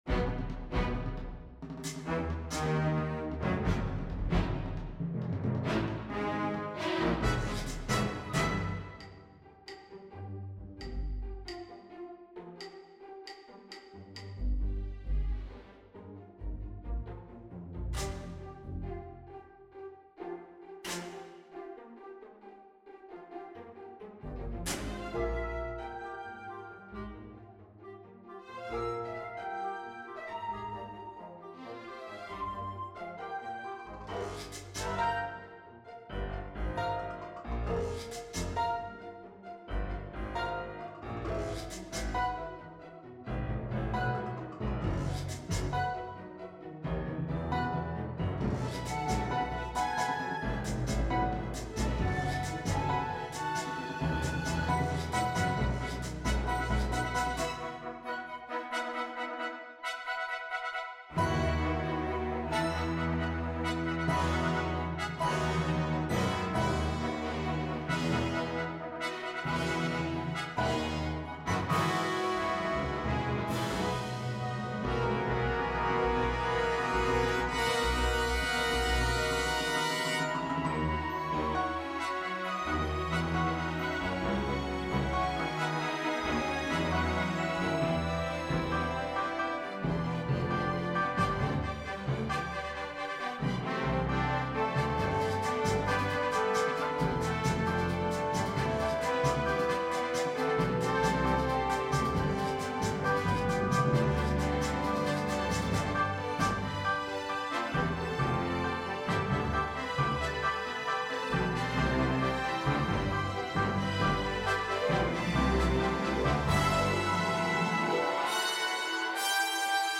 Theme: Multi-Cultural
Ensemble: Full Orchestra